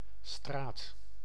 File:Nl-straat.ogg
Description male voice pronunciation for "straat" .